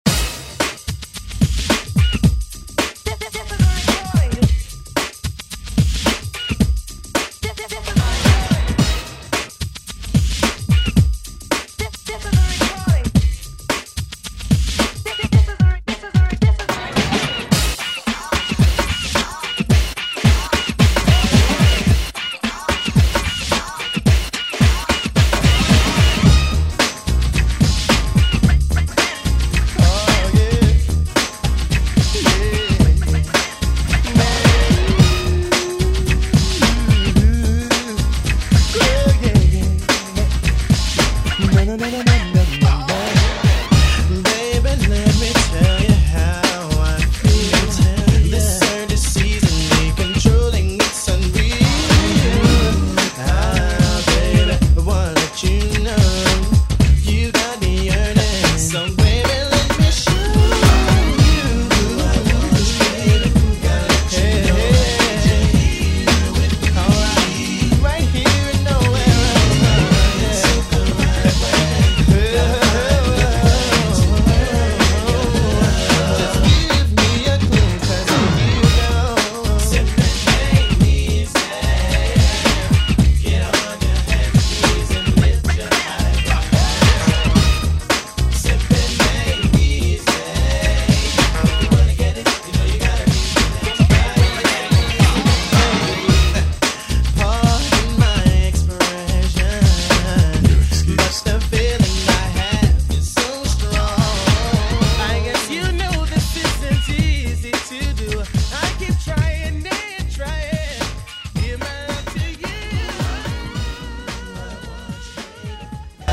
86 bpm
Genre: 2000's
Clean BPM: 86 Time